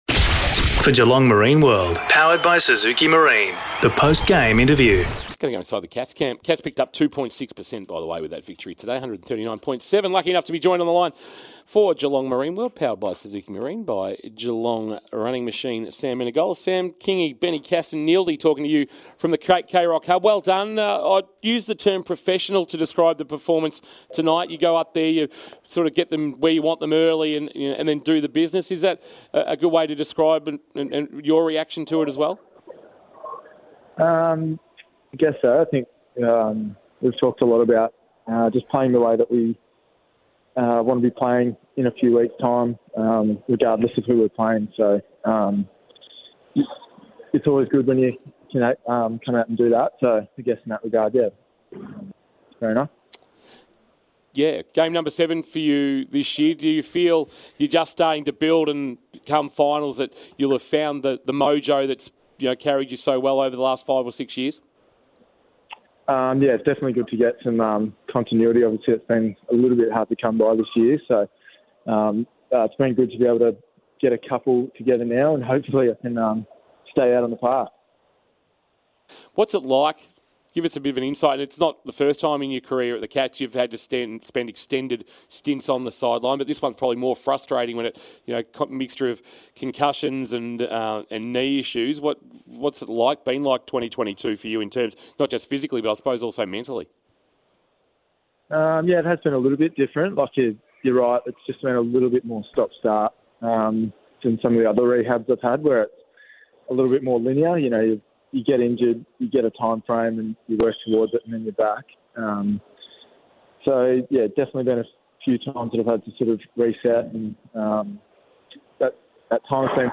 2022 – AFL ROUND 22 – GOLD COAST vs. GEELONG: Post-match Interview – Sam Menegola (Geelong)